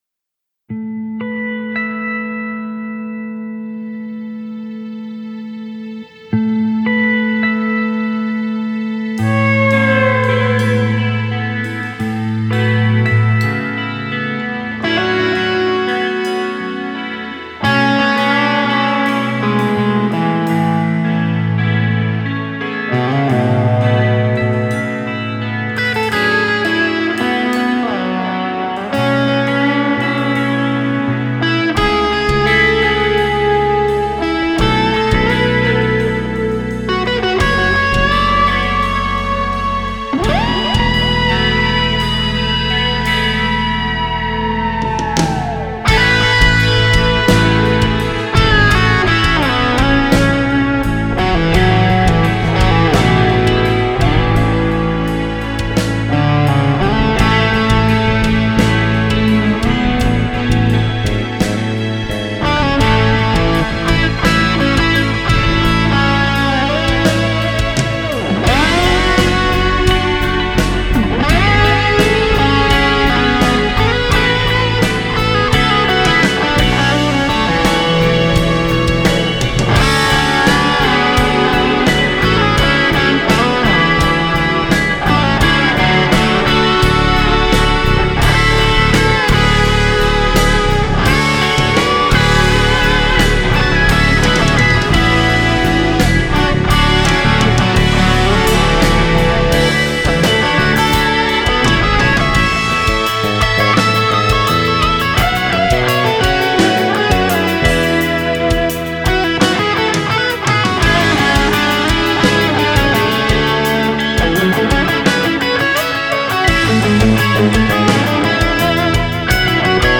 Refrão: D lídio, onde a nota D é pedal.